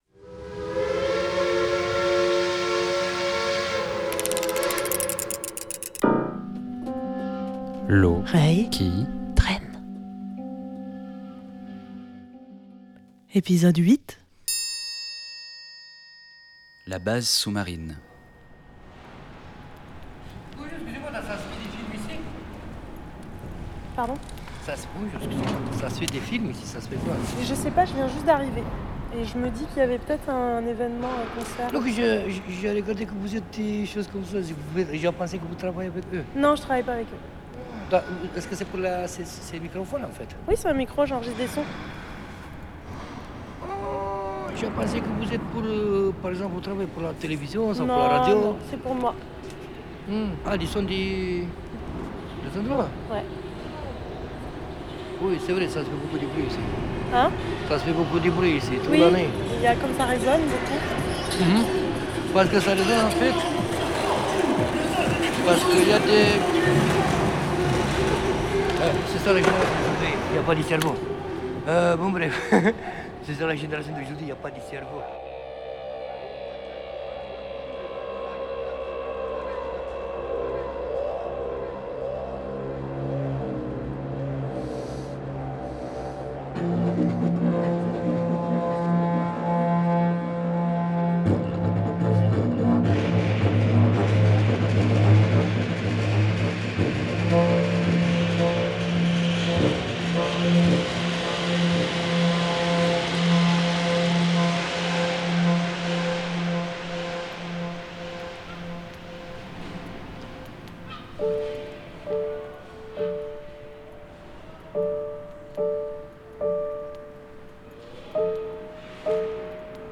Promenade sonore dans la base sous-marine de Saint-Nazaire
Improvisation de clarinette dans la base sous marine
claviers, programmations
voix et piano) Intermèdes au didjeridoo